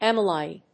/ˈɛmʌˌlaɪn(米国英語), ˈemʌˌlaɪn(英国英語)/